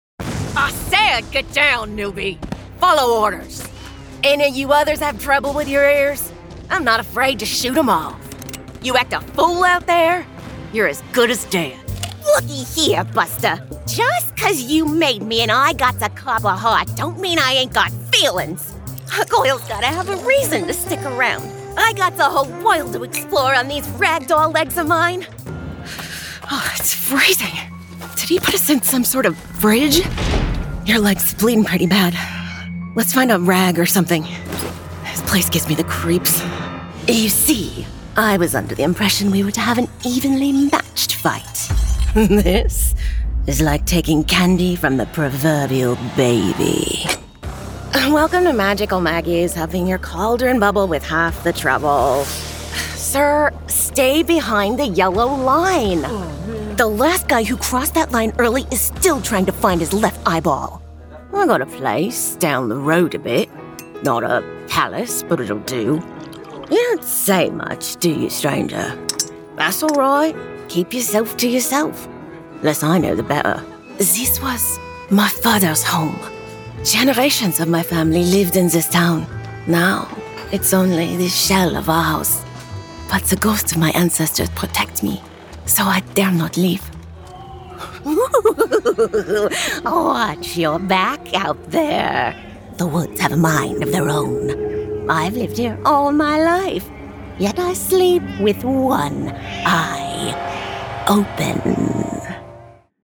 Videogames
A maioria do meu trabalho apresenta um dialeto americano neutro, mas também posso fornecer regionalismos conforme necessário.
Rode NT1
Mezzo-soprano